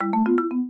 Example_UnlockSound.wav